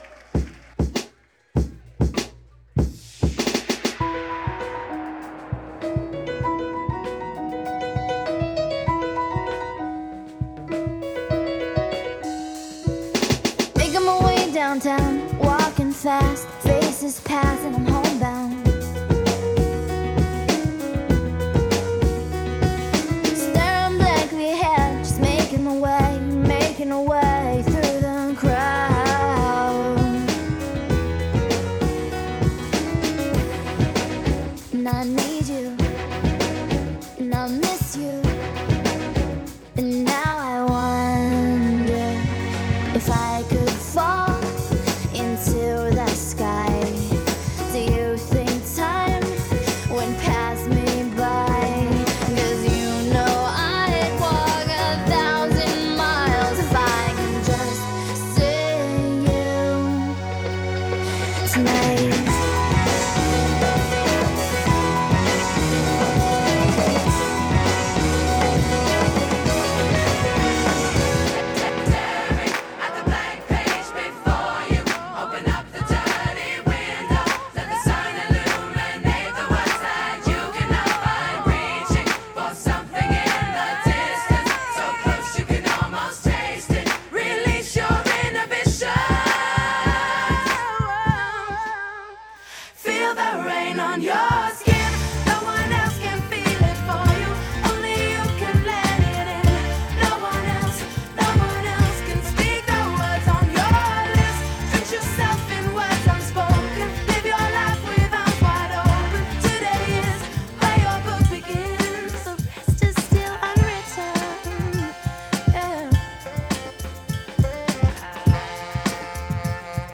A live wedding mix by Neon Transit recorded March 2026.